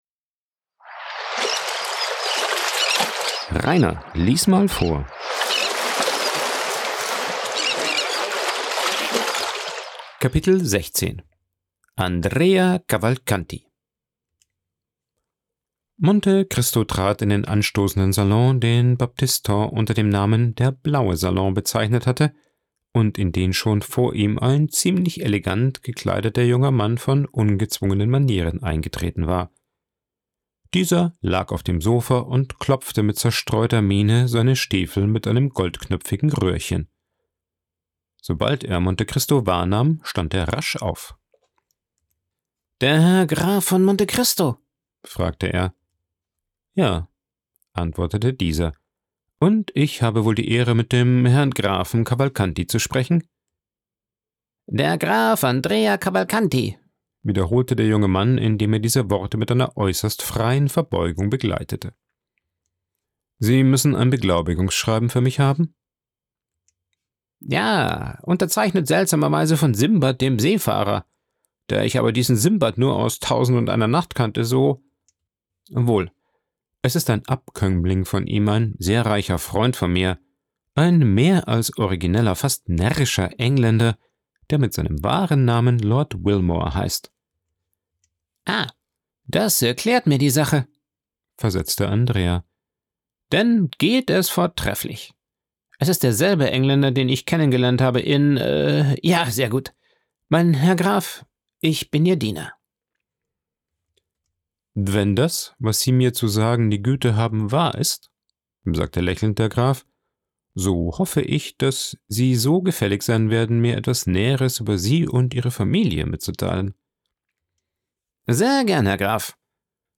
aufgenommen und bearbeitet im Coworking Space Rayaworx...